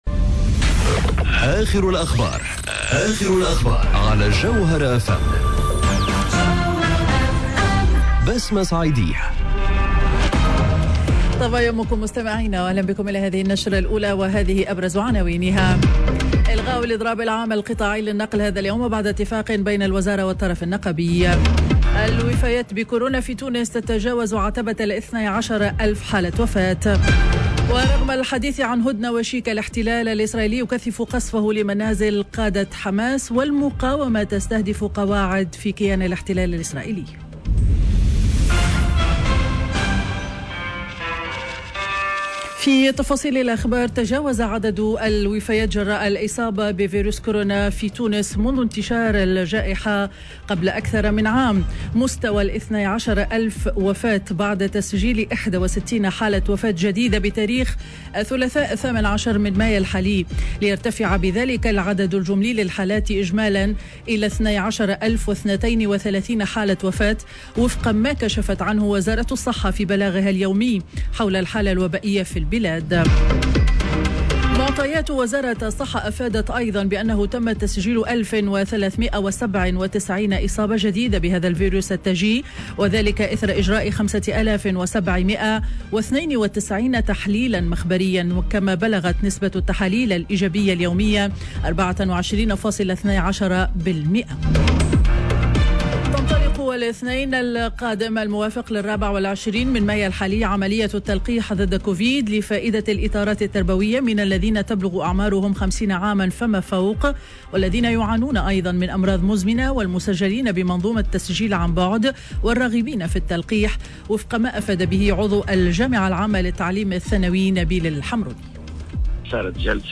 نشرة أخبار السابعة صباحا ليوم الخميس 20 ماي 2021